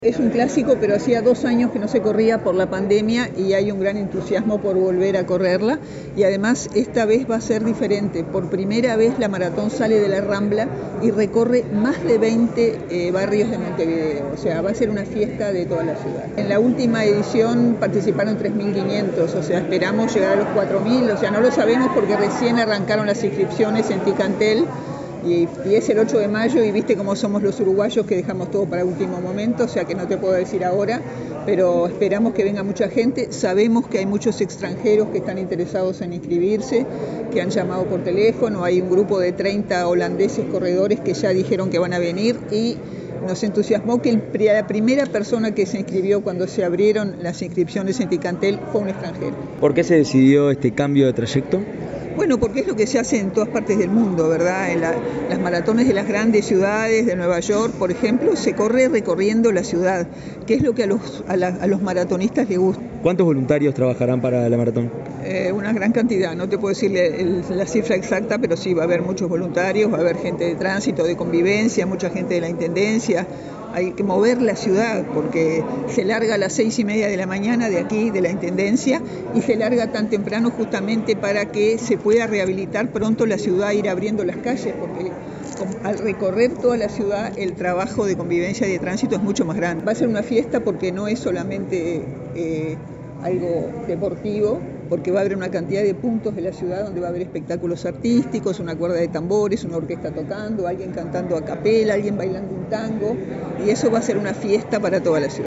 La directora de la Secretaría de Deportes, Educación Física y Recreación, Silvia Pérez, dijo en rueda de prensa que la carrera «es un clásico» que vuelve tras dos años de pandemia.